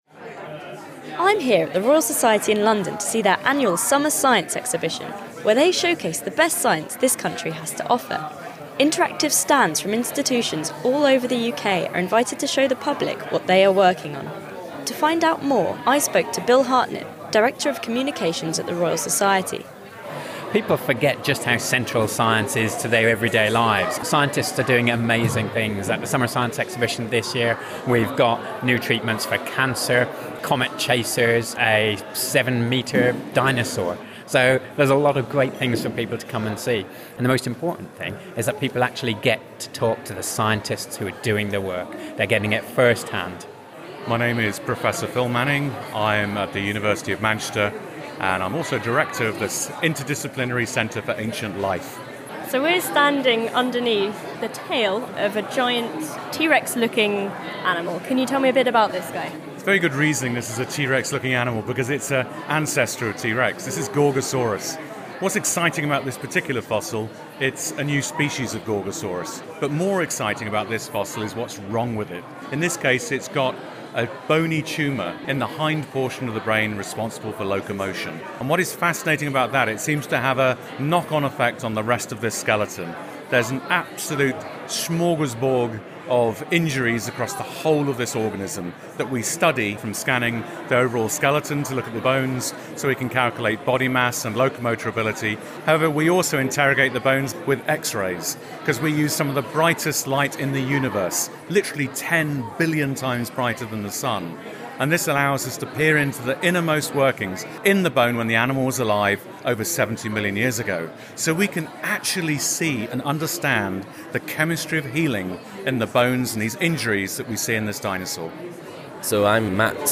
A look at some of the science on show at the Royal Society's Summer Science Exhibition.